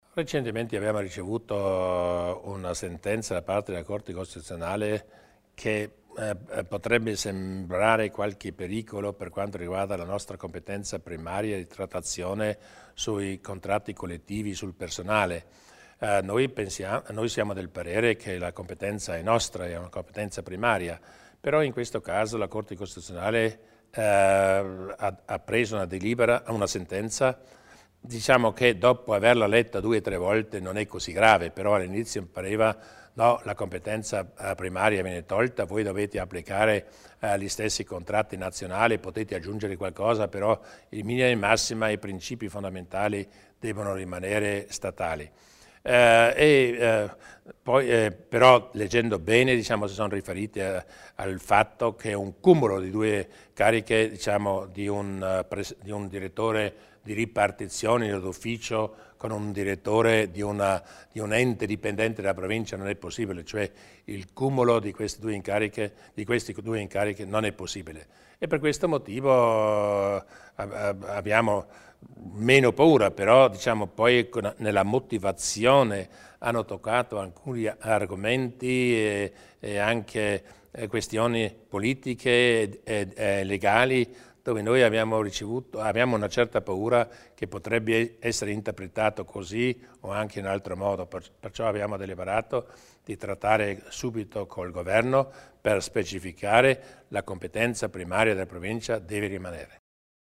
Il Presidente Durnwalder spiega i timori in tema di contratti collettivi